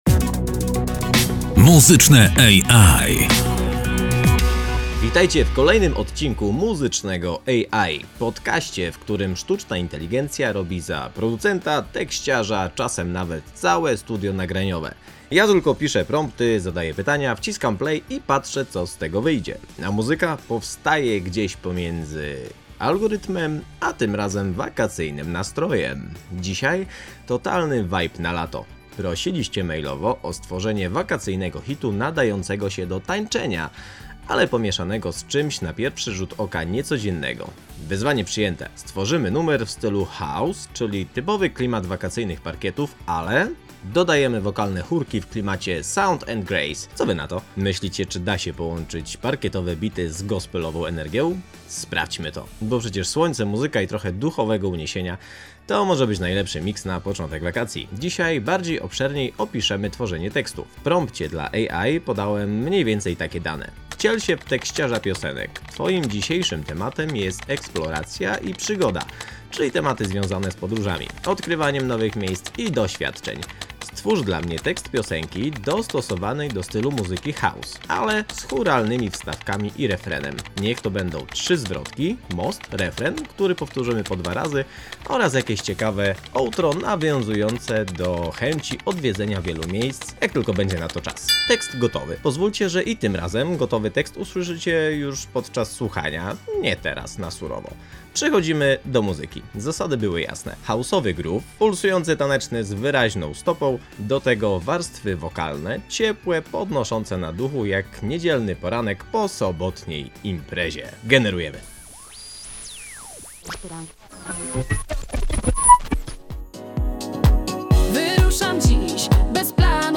Sztuczna inteligencja dostała konkretne zadanie: stworzyć tekst o eksploracji, podróżach i przygodzie – a wszystko to zanurzone w dźwiękach idealnych na letnie parkiety. Powstały trzy wersje utworu, ale tylko jedna oddała to, o co chodziło – pulsującą stopę, refren z chórami i przestrzeń, w której naprawdę można odetchnąć.
Dodaliśmy lekki wave w mostku, zachowaliśmy naturalną lekkość produkcji i zostawiliśmy trochę miejsca dla wyobraźni.